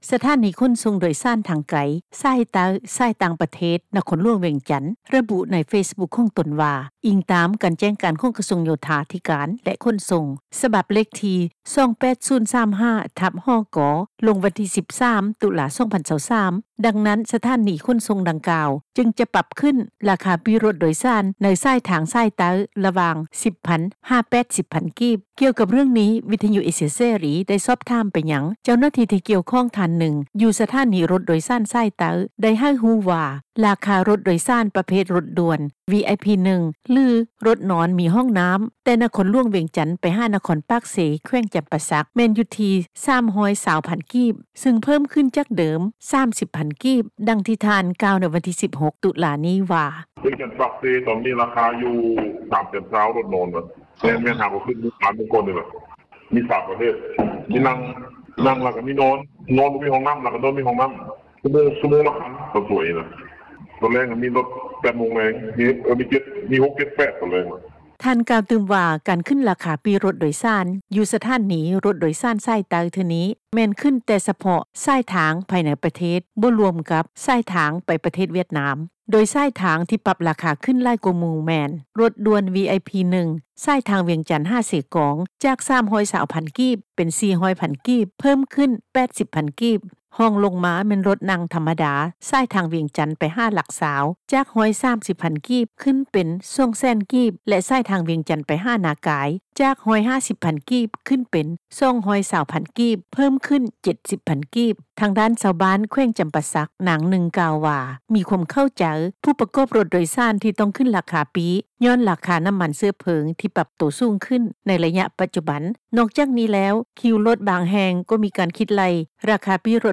ຊາວບ້ານ ຢູ່ແຂວງຈຳປາສັກ ນາງນຶ່ງ ກ່າວວ່າ ເຂົ້າໃຈຜູ້ປະກອບການຣົຖໂດຍສານ ທີ່ຕ້ອງໄດ້ຂຶ້ນລາຄາ ຍ້ອນນ້ຳມັນເຊື້ອເພີງ ທີ່ປັບໂຕສູງຂຶ້ນ ໃນໄລຍະປັດຈຸບັນ. ນອກຈາກນີ້ແລ້ວ, ຄິວຣົຖບາງແຫ່ງ ມີການຄິດໄລ່ຄ່າຣົຖໂດຍສານ ແບບດຽວກັນ ແຕ່ຣົຖທີ່ໄດ້ຂຶ້ນໂຕຈິງ ອາດຈະເປັນຣົຖນັ່ງທັມມະດາ ຊຶ່ງຖືວ່າ ເປັນການເອົາປຽບຜູ້ໂດຍສານ.